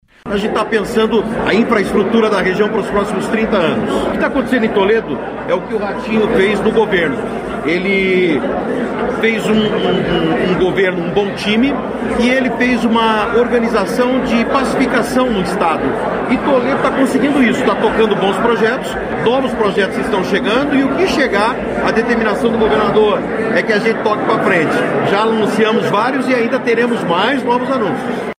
Sonora do secretário de Infraestrutura e Logística, Sandro Alex, sobre o investimento de R$ 45 milhões para construção de ponte estaiada em Toledo